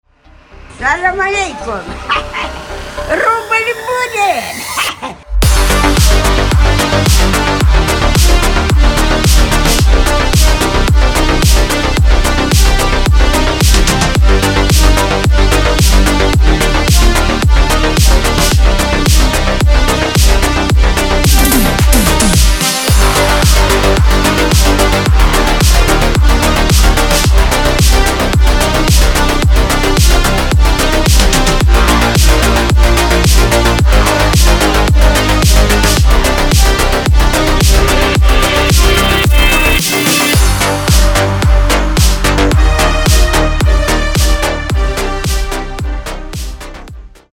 • Качество: 320, Stereo
remix
атмосферные
Electronic
забавный голос
synthwave
DnB
darksynth